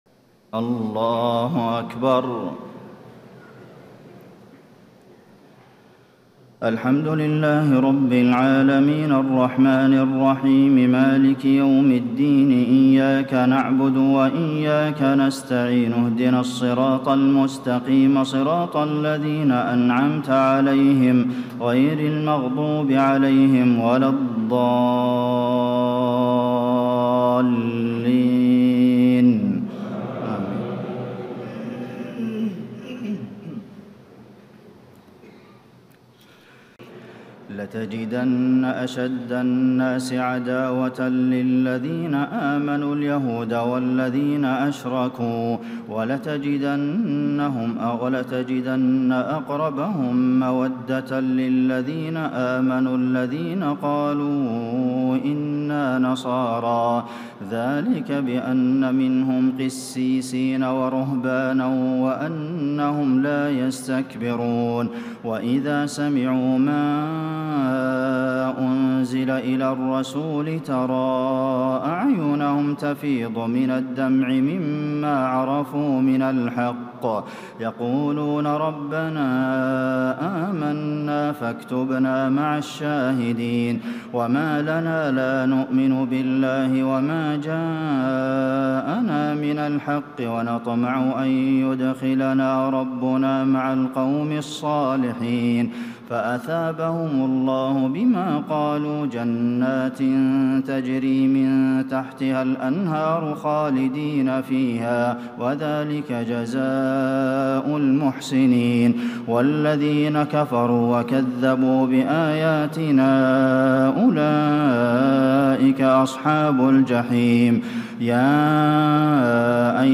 تهجد ليلة 27 رمضان 1436هـ من سورتي المائدة (82-120) و الأنعام (1-45) Tahajjud 27 st night Ramadan 1436H from Surah AlMa'idah and Al-An’aam > تراويح الحرم النبوي عام 1436 🕌 > التراويح - تلاوات الحرمين